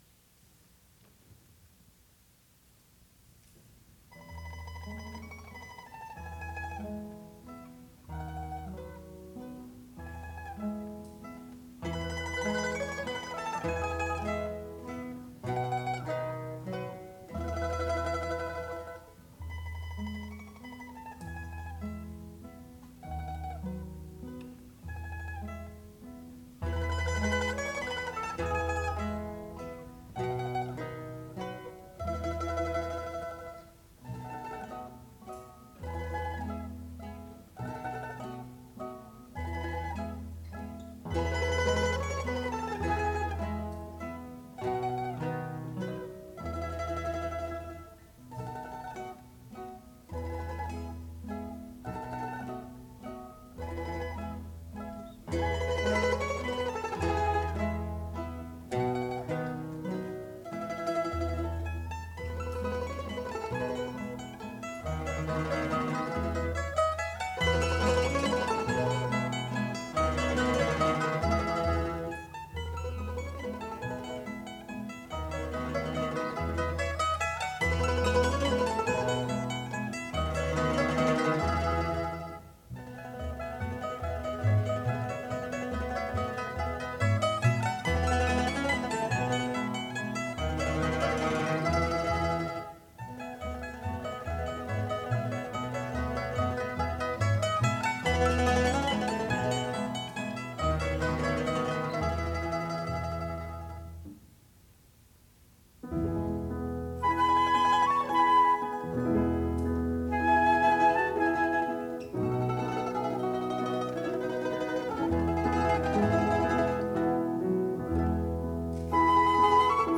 第2回定期演奏会 (1971.7.5 府立文化芸術会館)